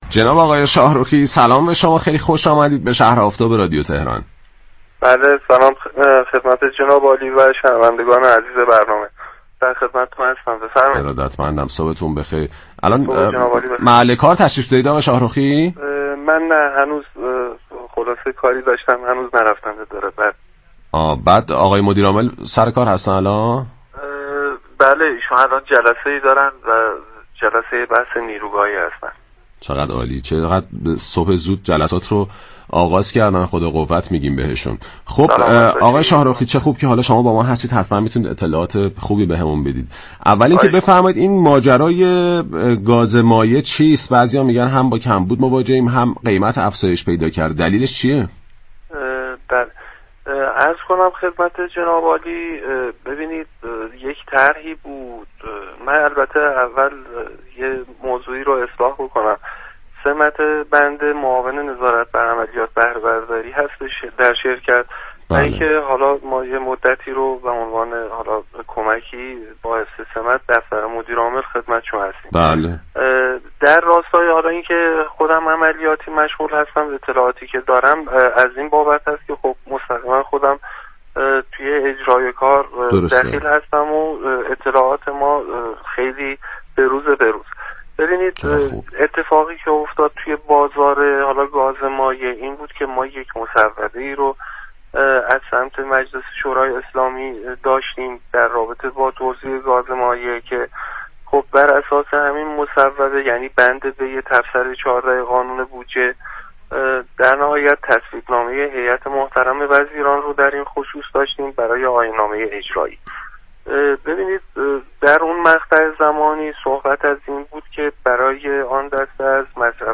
در گفت و گو با «شهر آفتاب»